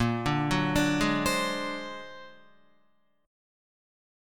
Bb+9 chord